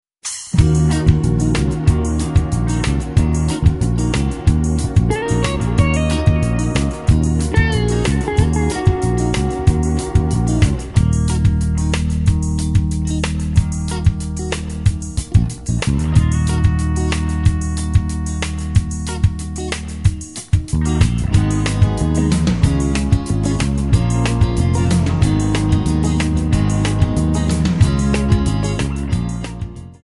Backing track files: Ital/French/Span (60)